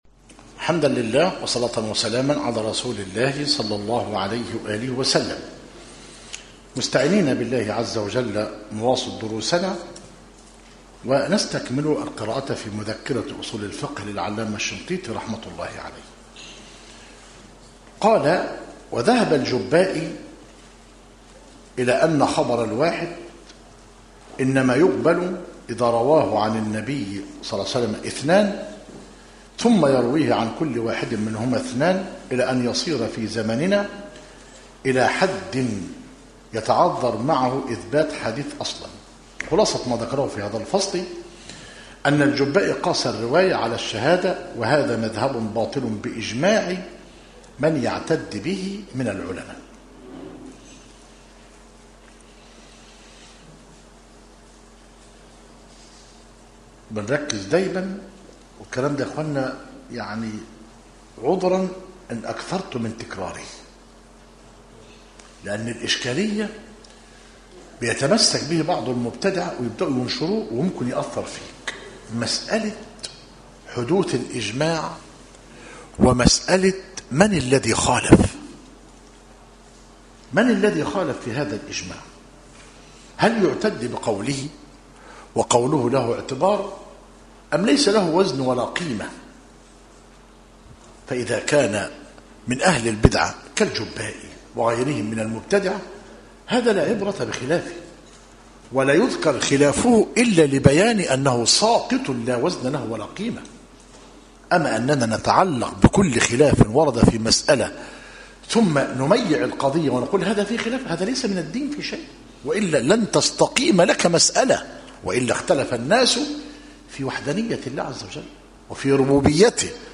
مذكرة أصول الفقه للعلامة الشنقيطي رحمه الله - مسجد التوحيد - ميت الرخا - زفتى - غربية - المحاضرة التاسعة والأربعون - بتاريخ 21- محرم - 1438هـ الموافق 22 - أكتوبر- 2016 م